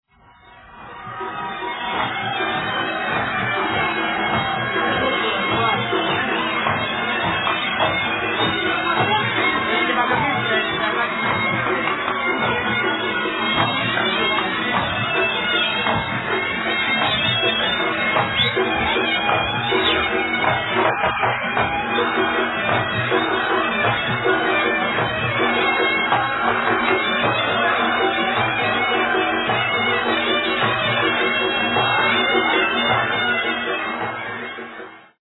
PERFORMING MUSICIANS FROM MOROCCO, ALGERIA, TUNISIA, LIBYA
Tunisian Winds and Percussion